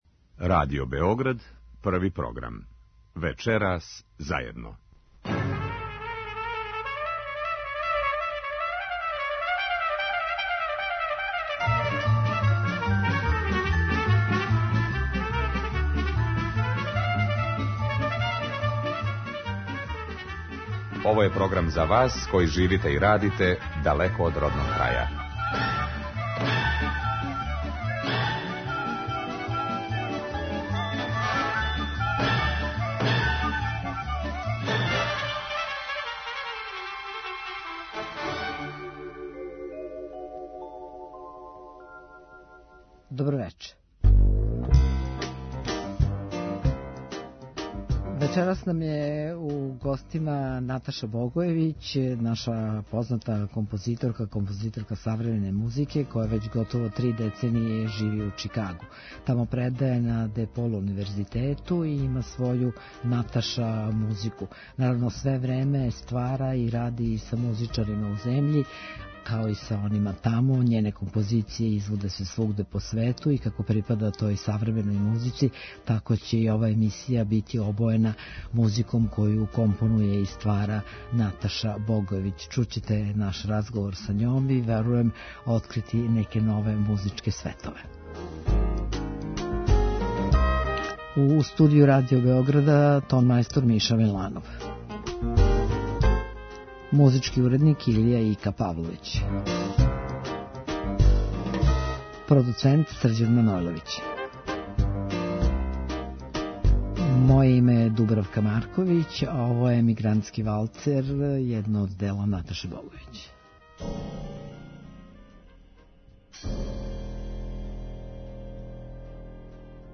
Уз причу, слушаћемо и њену музику.